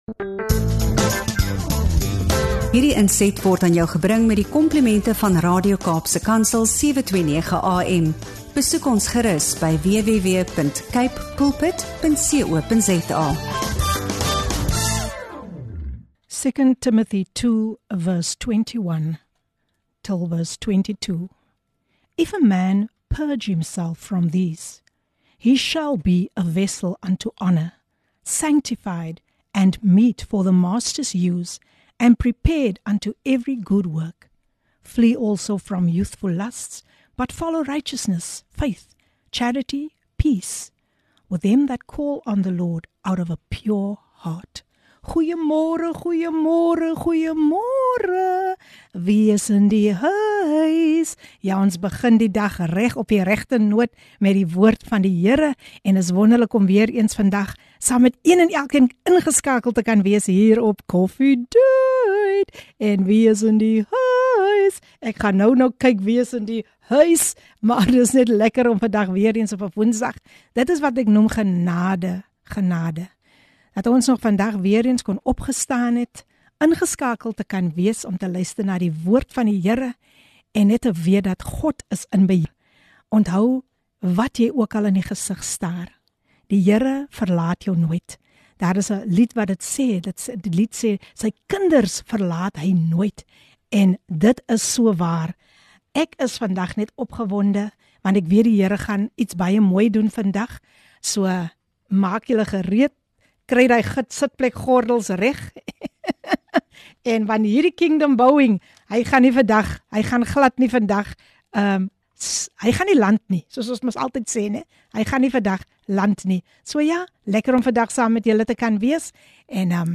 'n Kragtige gesprekke met inspirerende gaste, pragtige musiek en 'n dosis van geloofsgevulde aanmoediging deel.